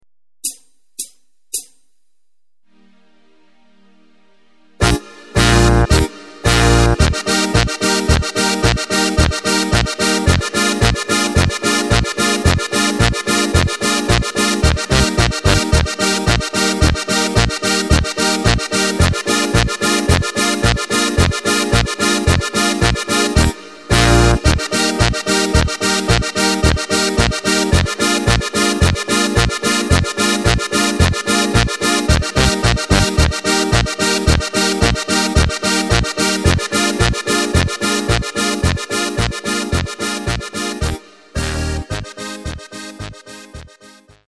Takt:          2/4
Tempo:         110.00
Tonart:            Eb
Polka für Steirische Harmonika!
Playback mp3 Demo